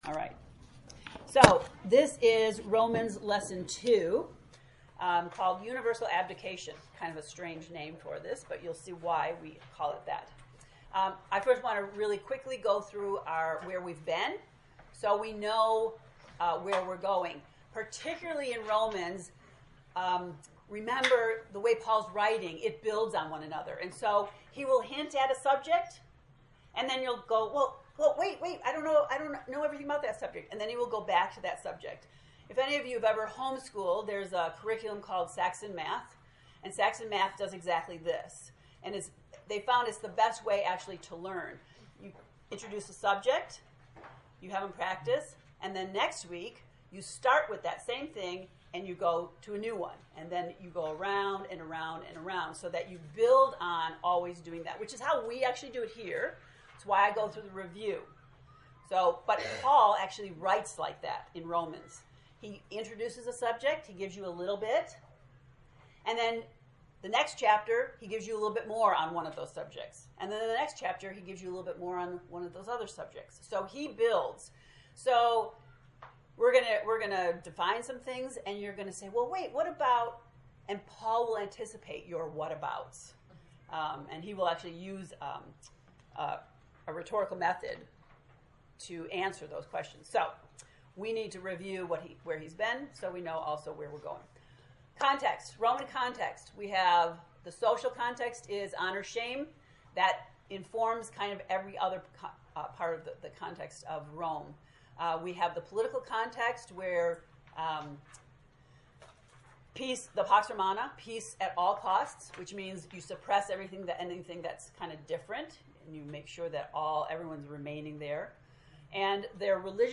To listen to the lecture, “Universal Abdication,” click below: